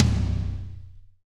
-DRY TOM 1-R.wav